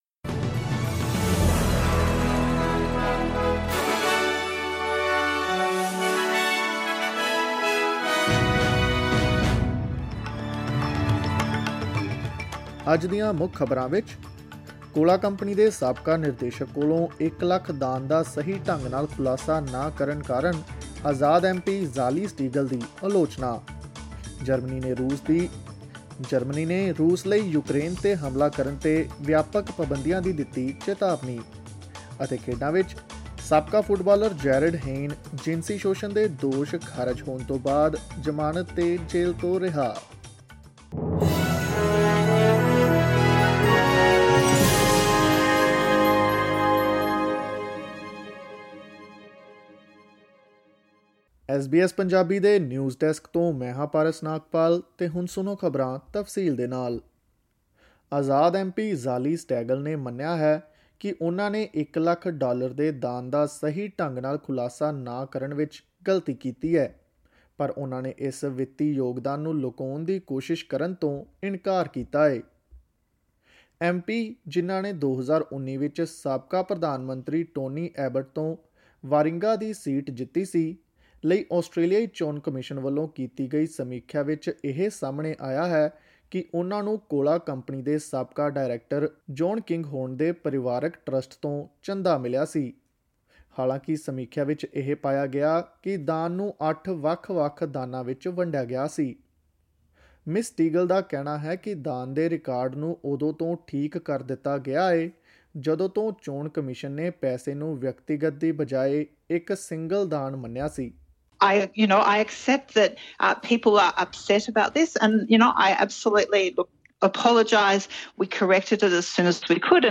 Presenting the major national and international news stories of today; sports, currency exchange rates and the weather forecast for tomorrow. Click on the audio button to listen to the news bulletin in Punjabi.